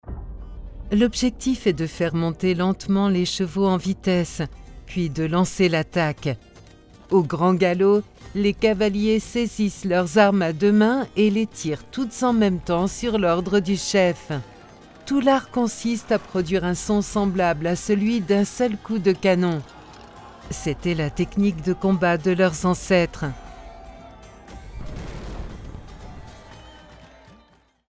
I am a french voice over Chic, Deep, Warm, Seductive a radio voice in FIP (Radio France) Voice over for documentaries on French TV : France 3, France 5, jimmy and ARTE Commercial, Corporate, E-learning, audiobook...
französisch
Sprechprobe: Sonstiges (Muttersprache):
Documentary_Maroco.mp3